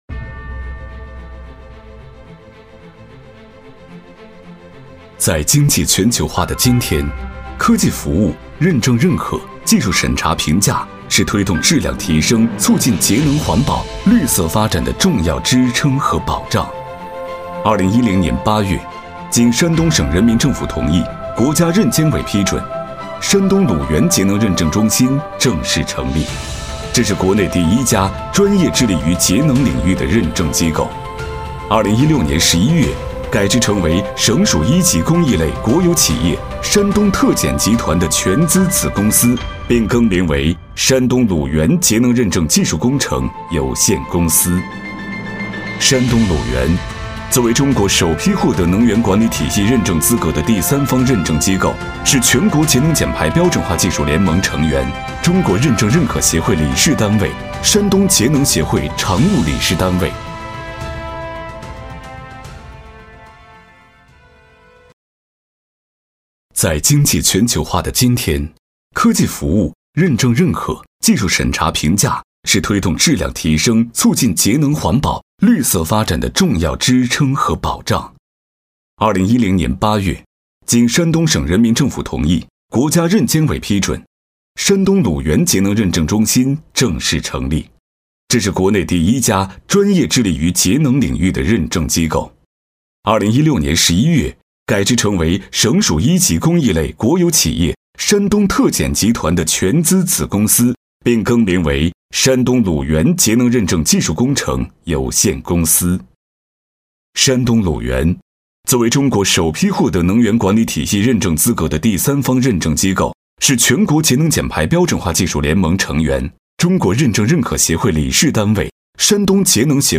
190男-沧桑大气
特点：大气浑厚 稳重磁性 激情力度 成熟厚重
企业专题——山东鲁源【大气厚重】.mp3